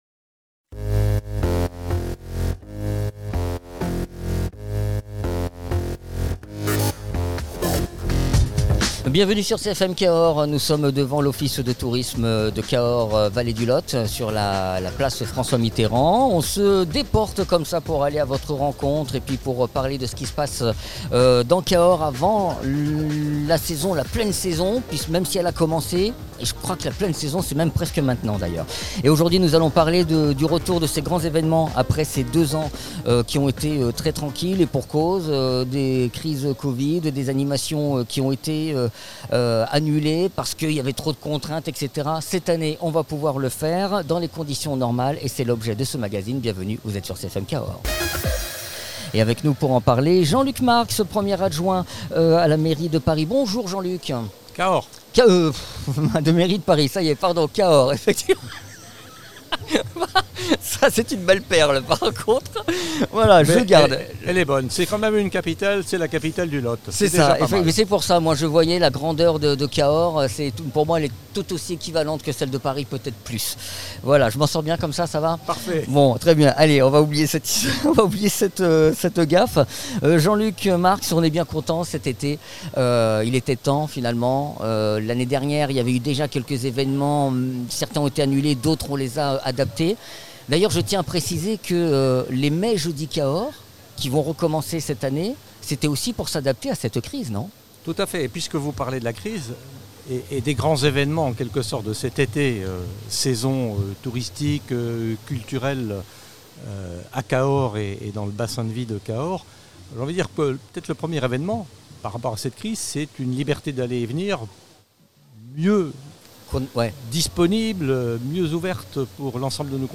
Invité(s) : Jean Luc Marx, premier adjoint au Maire de Cahors